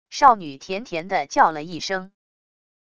少女甜甜地叫了一声wav音频